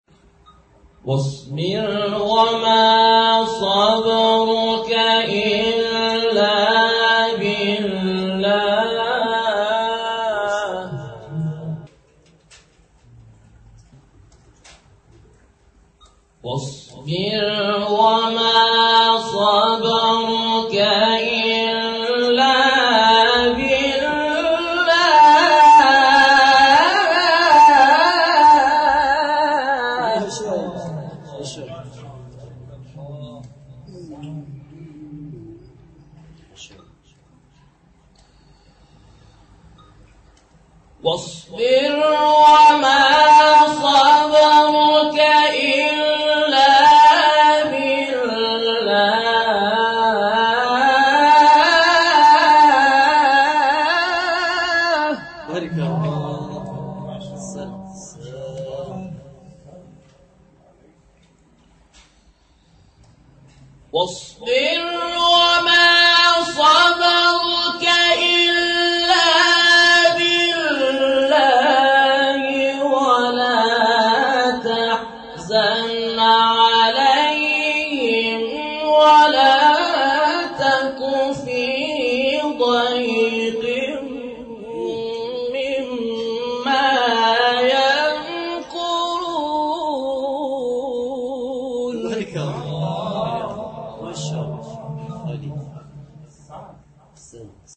شبکه اجتماعی: نغمات صوتی از تلاوت قاریان برجسته و ممتاز کشور که به‌تازگی در شبکه‌های اجتماعی منتشر شده است، می‌شنوید.